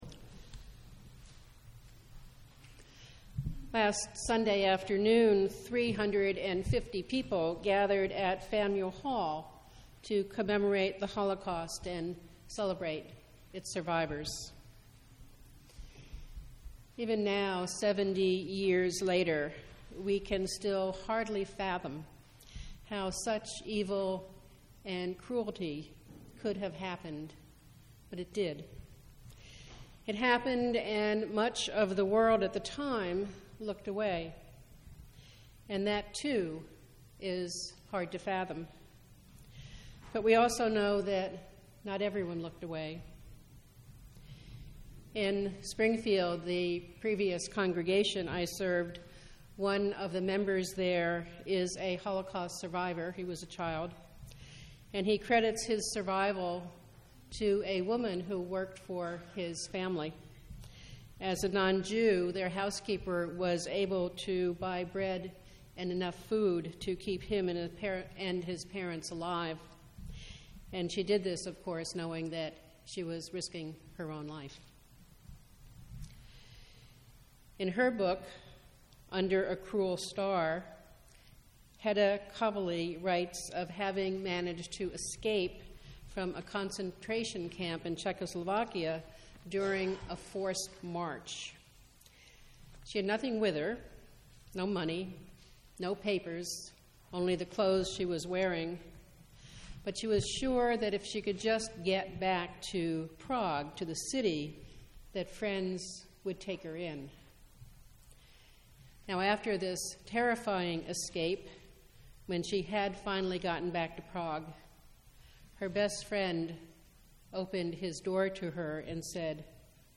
Two Who Dared: A Sermon in Commemoration of Holocaust Sunday – April 14, 2013